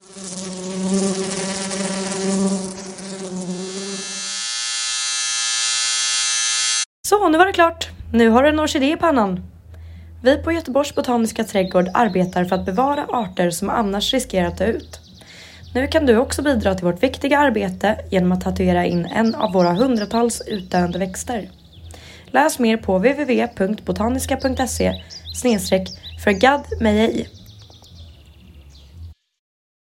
Poddreklam.
förgaddmigej-poddreklam.mp3